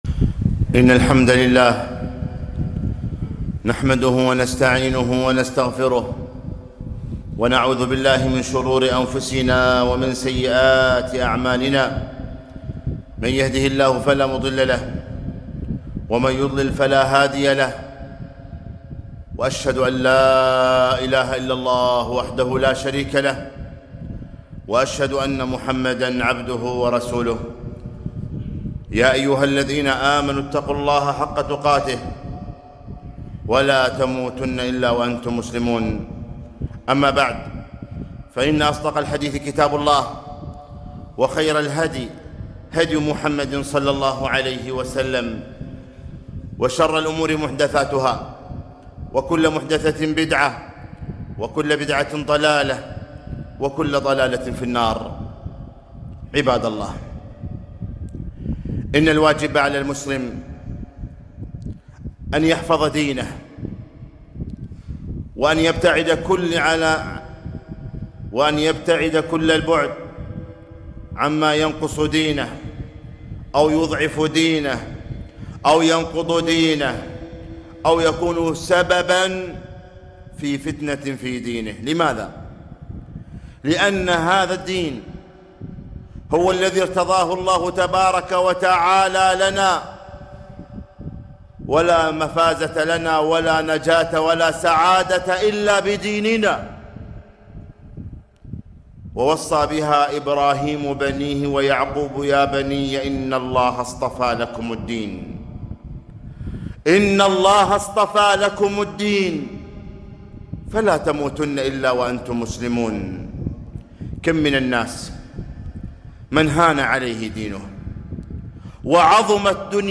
خطبة - لا تضيع دينك